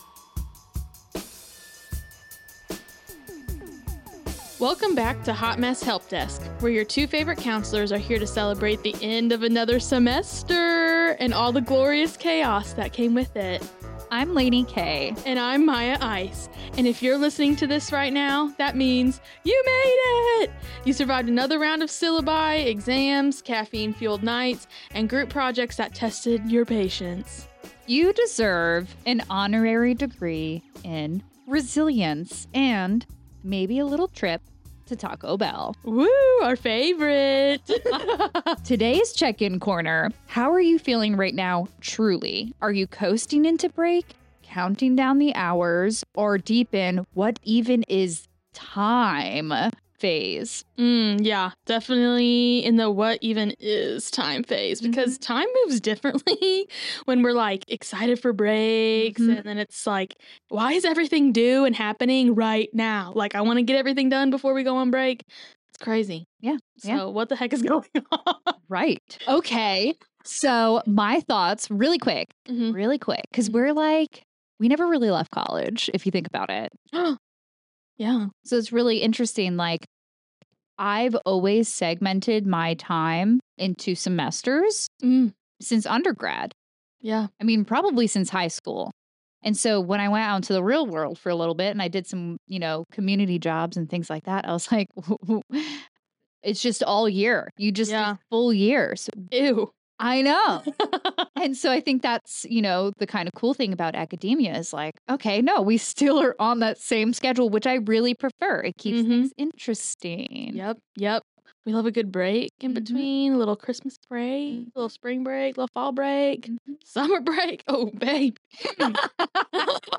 We are two mental health counselors here to say-same.